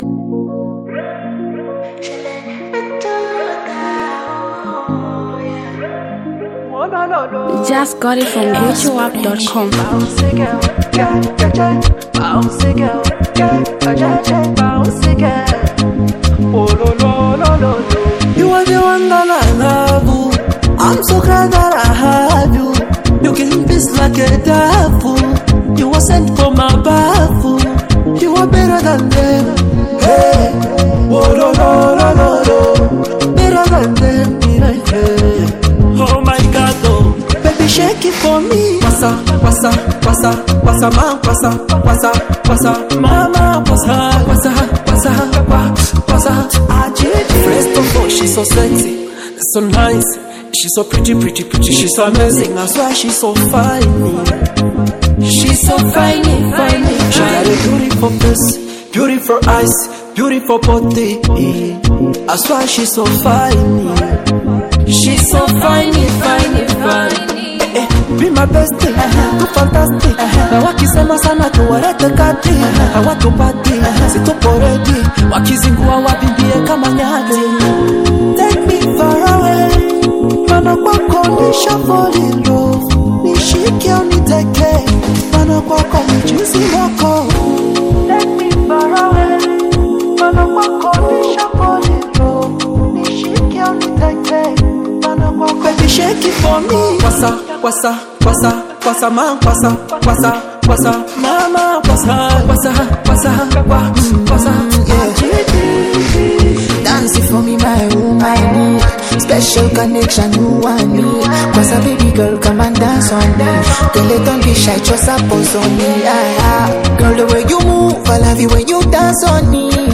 powerful dancehall record